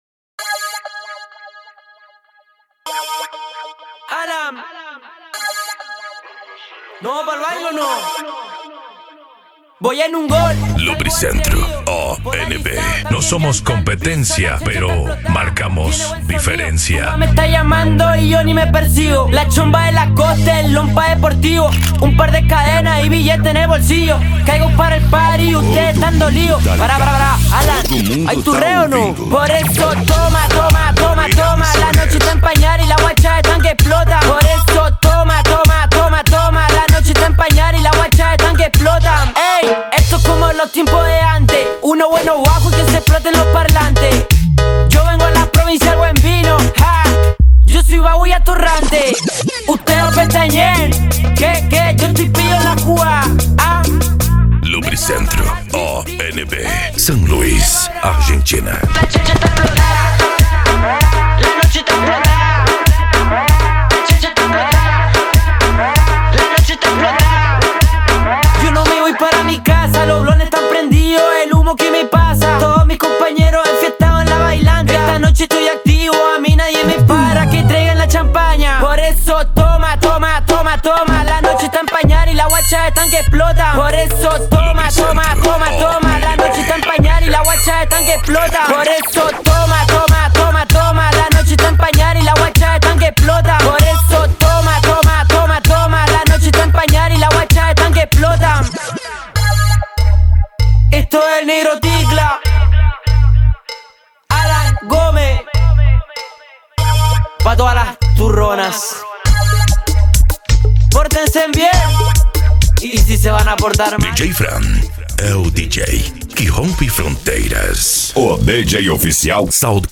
Cumbia
Funk
Remix